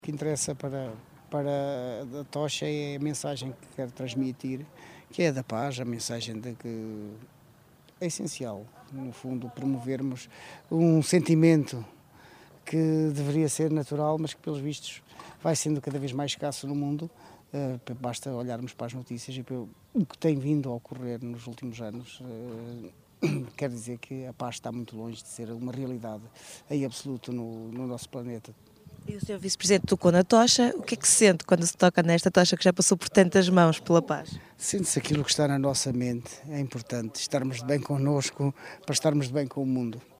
Uma forma de espalhar a importância de estabelecer a paz mundial, considera o vice-presidente da Câmara Municipal de Macedo de Cavaleiros, Carlos Barroso.